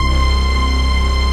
HOUSPAD01.wav